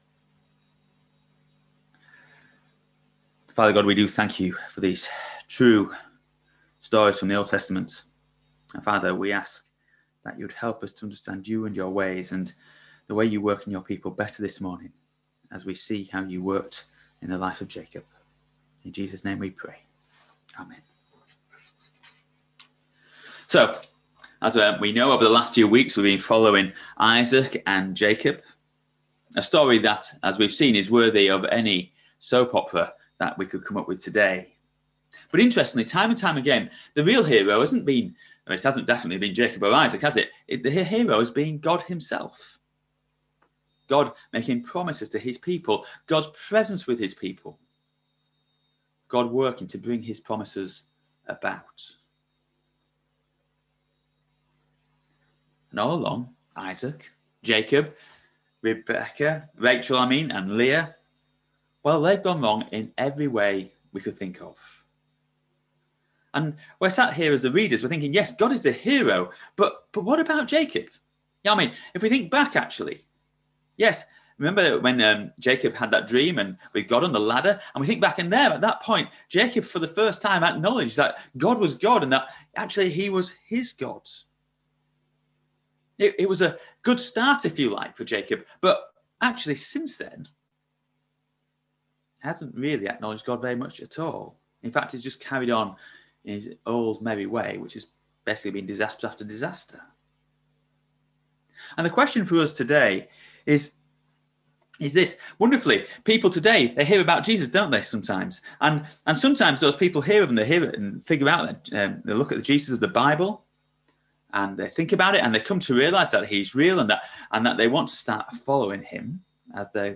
Genesis 30v25-31v55 Service Type: Sunday Morning Service Topics